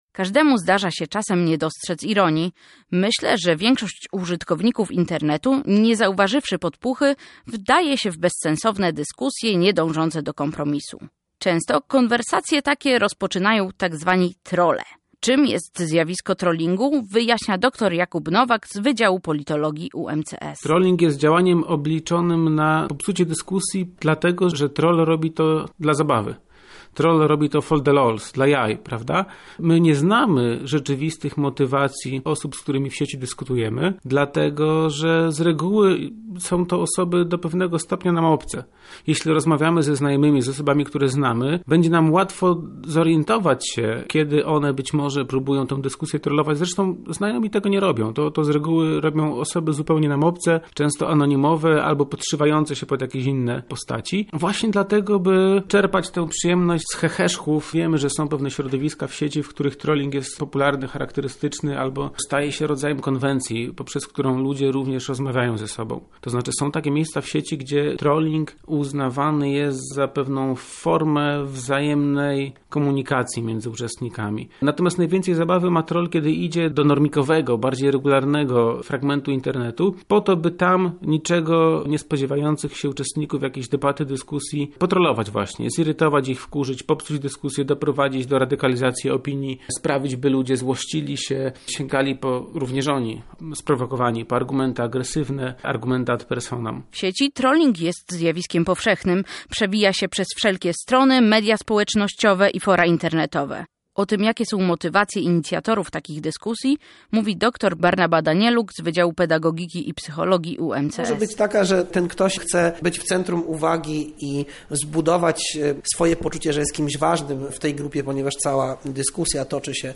Niektóre wpływają na emocje i samopoczucie użytkowników sieci, przykładem jest trolling. Temu zjawisku przyjrzała się nasza reporterka: